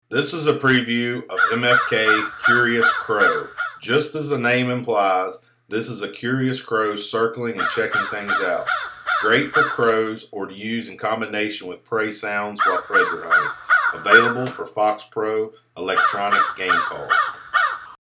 Recorded with the best professional grade audio equipment MFK strives to produce the highest